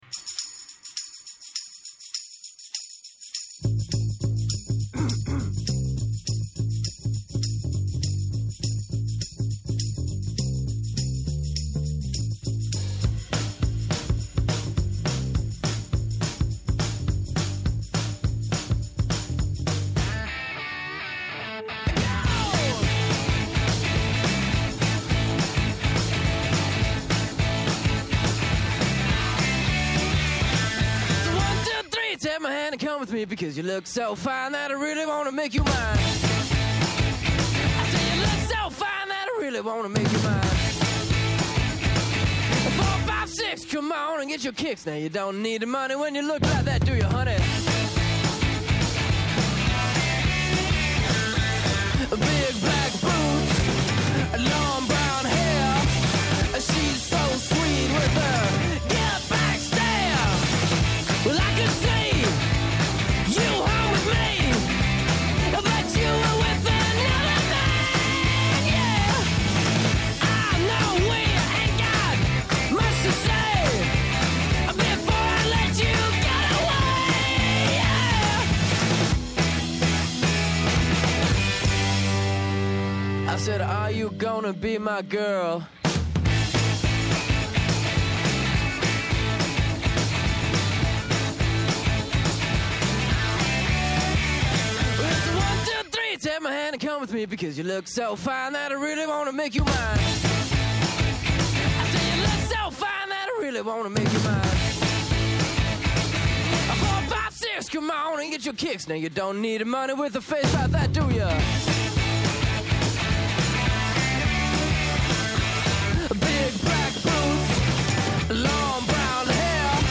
Καλεσμένος σήμερα ο Κώστας Ζαχαριάδης , μέλος πολιτικής γραμματείας ΣΥΡΙΖΑ.